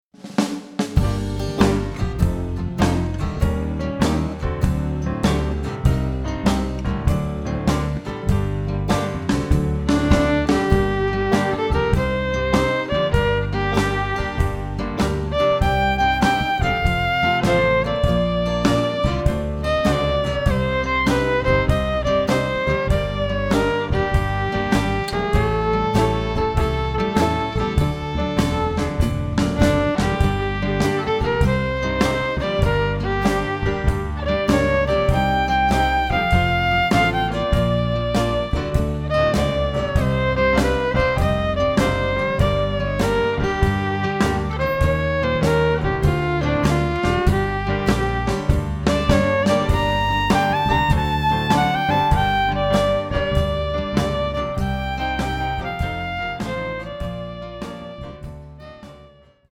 turns swingingly dark and atmospheric